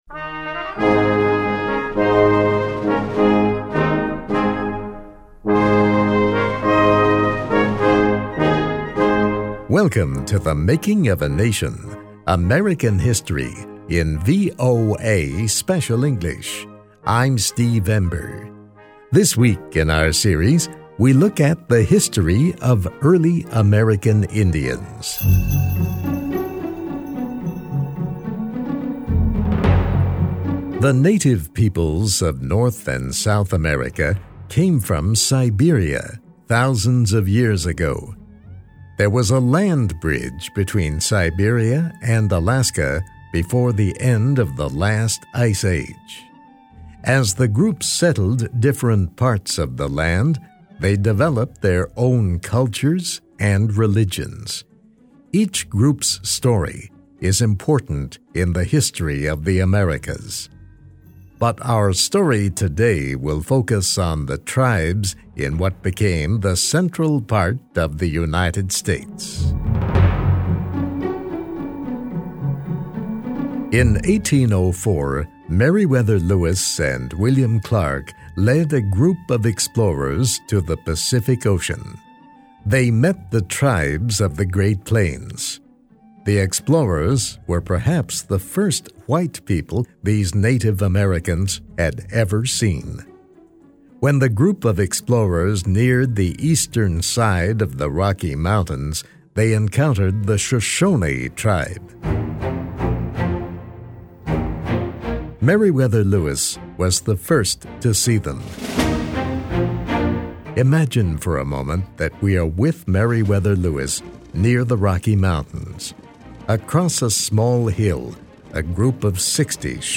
VOA Special English, American History: Westward Expansion Brings Explorers, Settlers in Contact with the Plains Indians-The Making of a Nation.